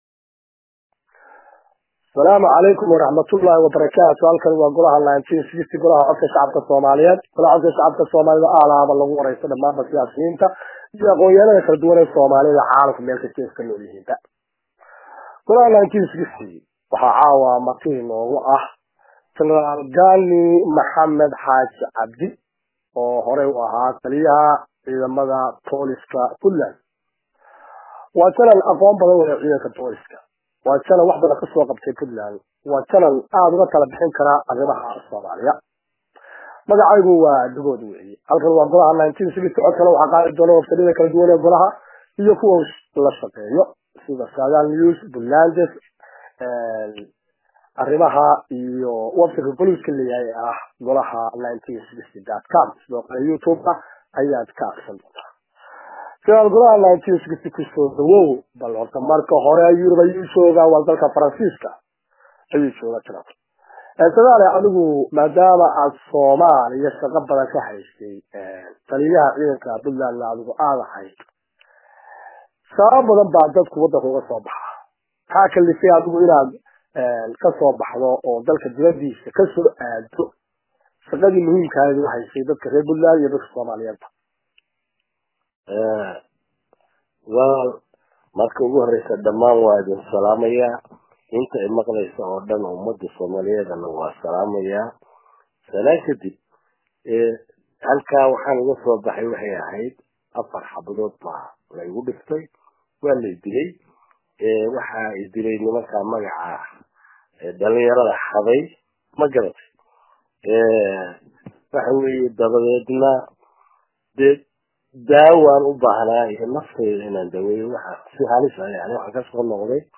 Dhagayso: Janaraal Gaani maxamed x Cabdi oo waraysi dheer siiyey Golaha1960